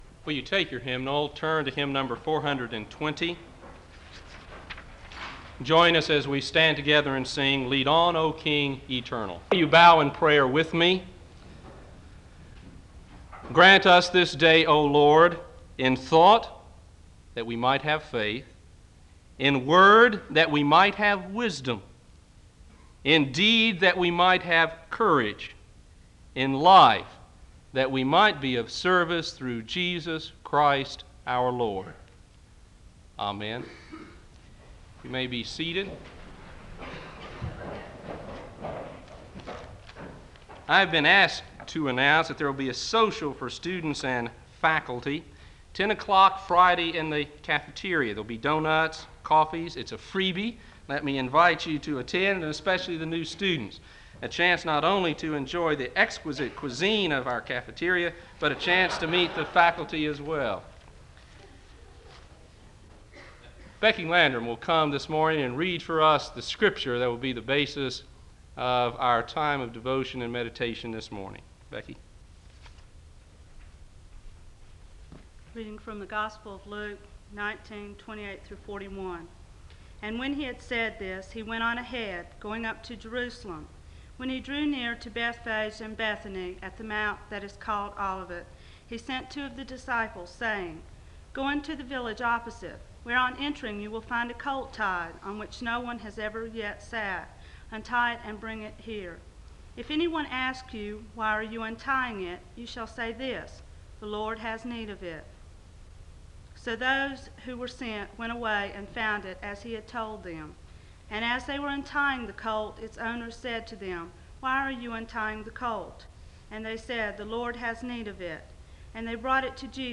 The service opens with a word of prayer, an announcement, and a public reading of Scripture from Luke 19:28-41 (00:00-02:50).
SEBTS Chapel and Special Event Recordings